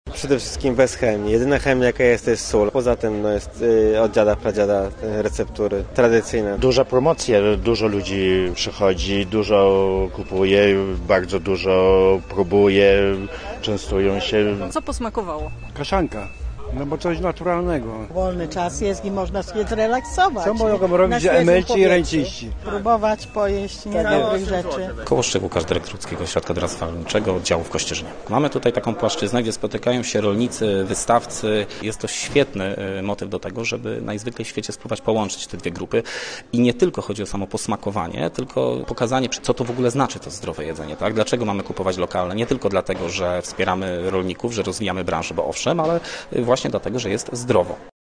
Posłuchaj relacji: Nazwa Plik Autor Targi Rolniczo-Ogrodnicze w Kościerzynie audio (m4a) audio (oga) Impreza w sobotę i niedzielę trwać będzie w godzinach od 8.00 do 16.00. Dodatkową atrakcją będzie pokaz kulinarny Jakuba Kuronia.